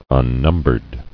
[un·num·bered]